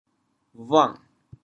调: 弟 国际音标 [buaŋ]
bhuang6.mp3